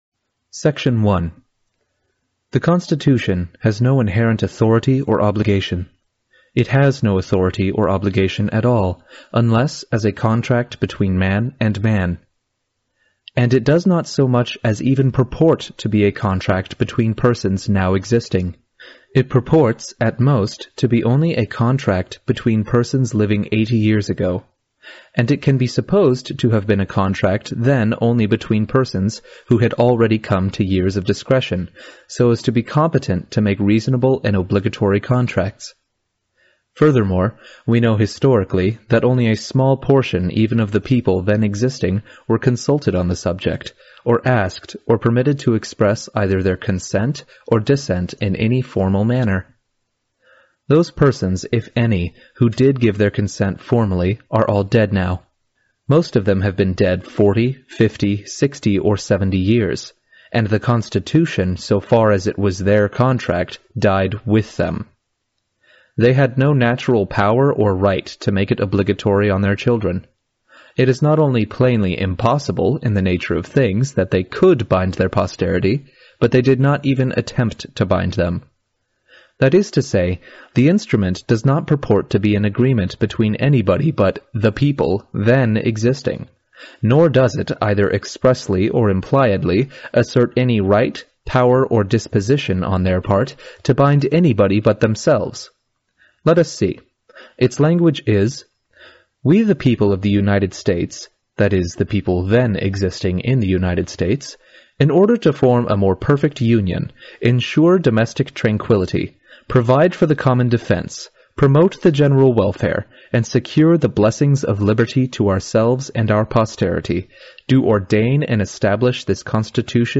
No Treason: The Constitution of No Authority [Complete Audiobook w/ Subtitles] | Lysander Spooner